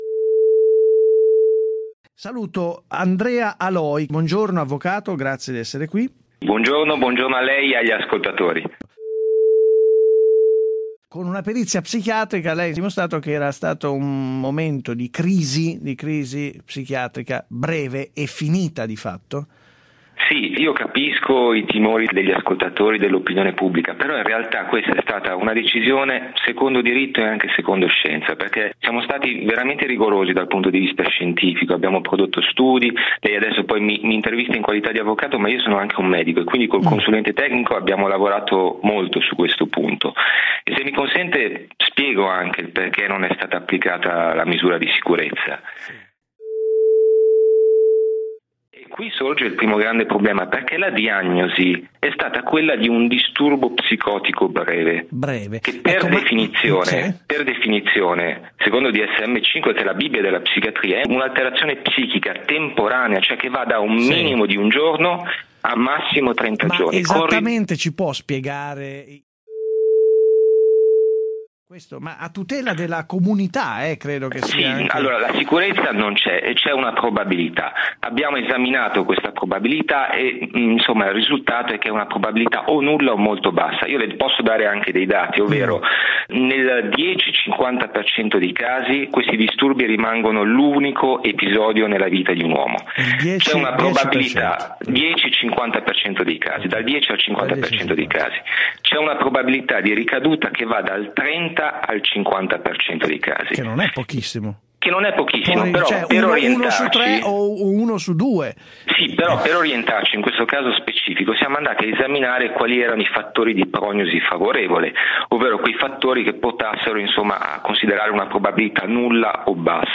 In questa intervista radiofonica raccontiamo un caso di tentato omicidio in cui diritto e scienza si sono intrecciati.
intervista-radio24-edit.mp3